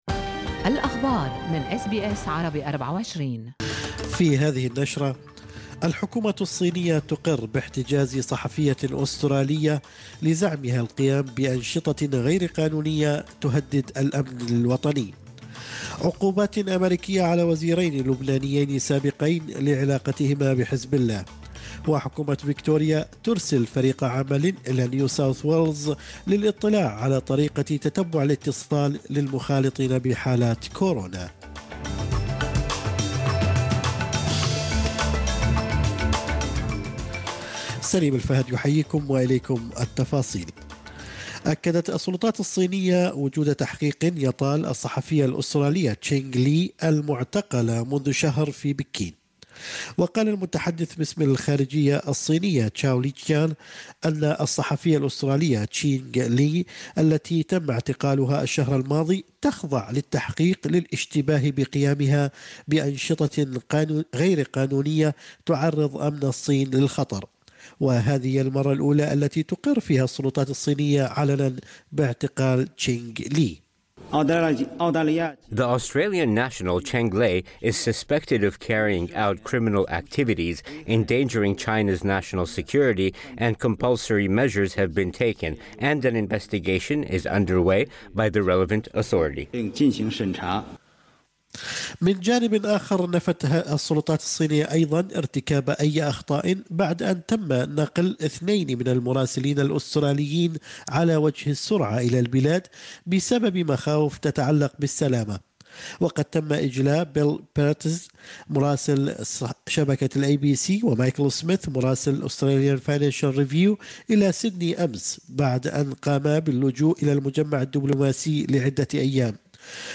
نشرة أخبار الصباح 9/9/2020